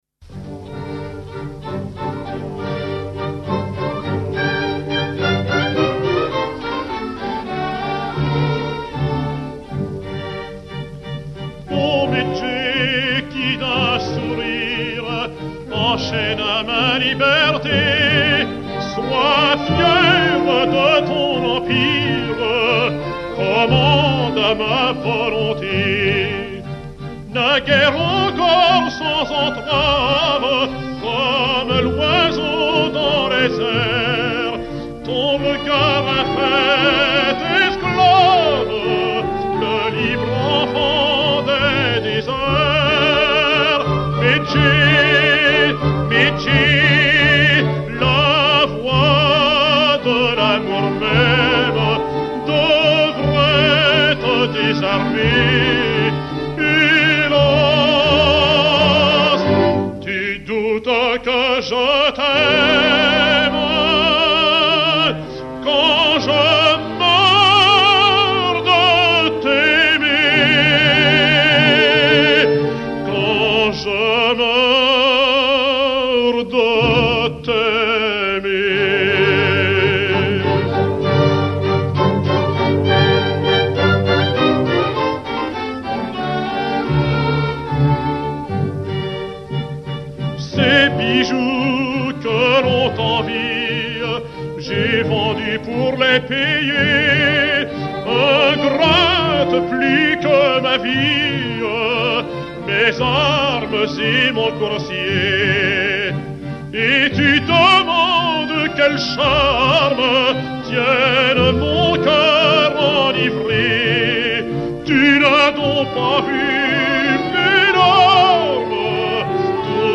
Chanson arabe